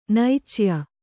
♪ naicya